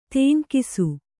♪ tēŋkisu